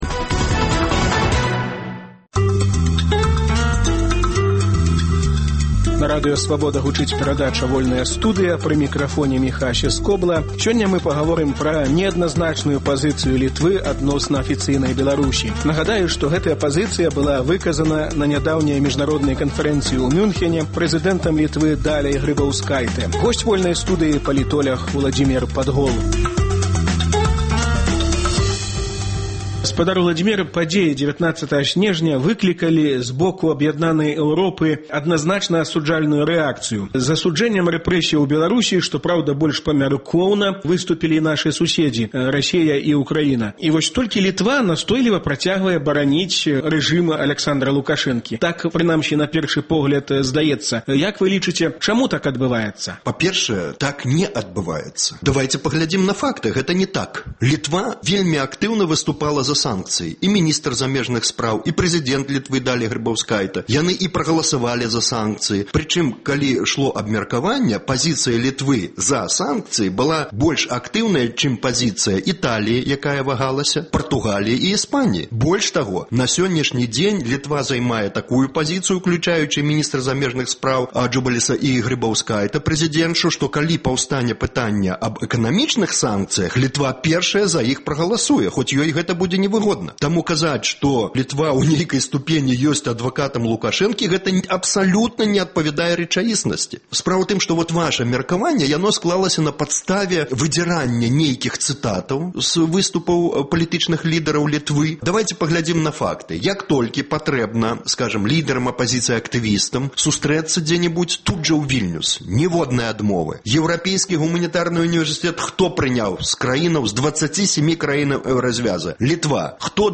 Размова пра неадназначную пазыцыю Літвы адносна Беларусі.